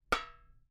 sfx_walk_metal_2.mp3